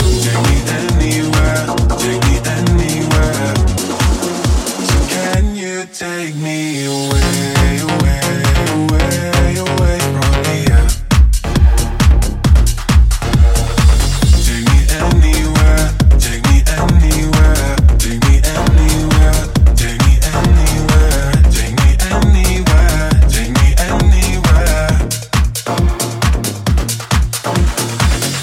Genere: pop, slap, deep, house, edm, remix